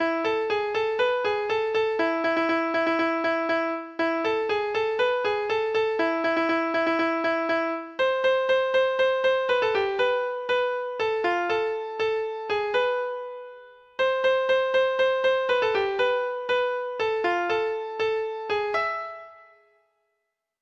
Folk Songs
Traditional Music of unknown author.